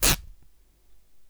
Desgarre de tela
Sonidos: Hogar